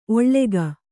♪ oḷḷega